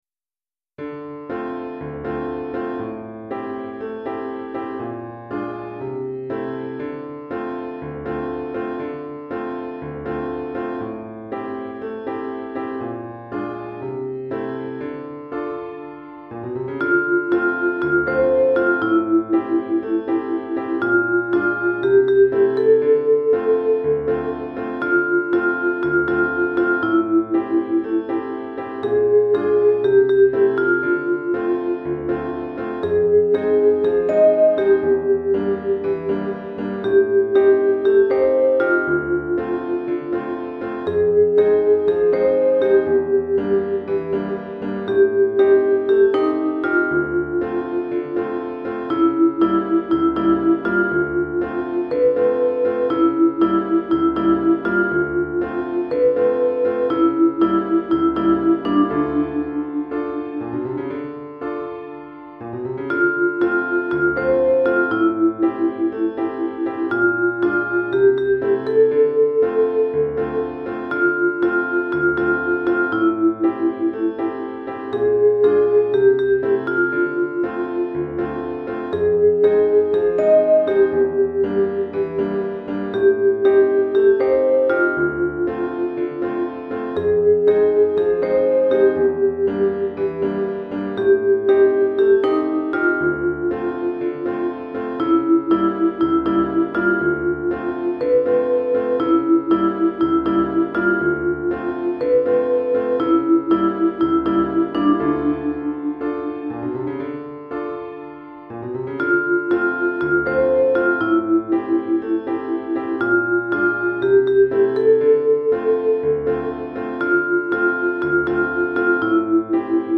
Chorale d'Enfants et Piano ou Guitare